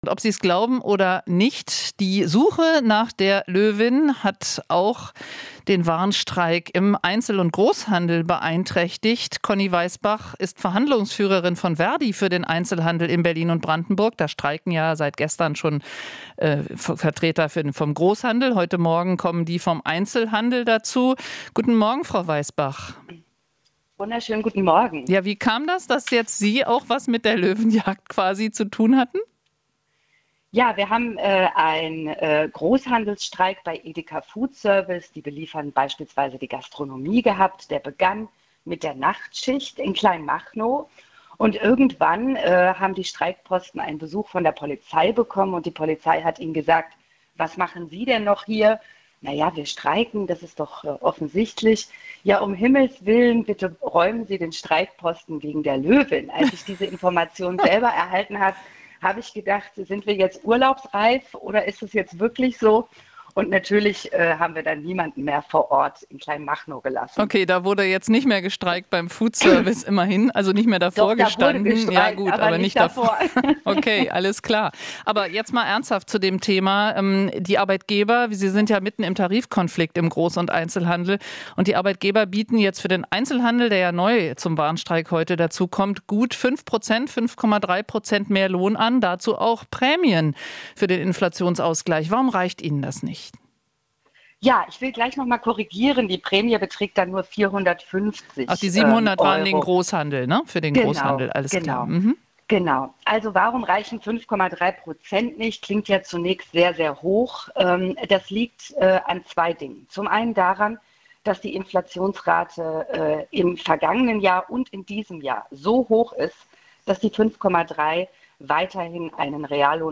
Interview - Streik im Einzelhandel: "Es geht an die Grenzen der Existenz"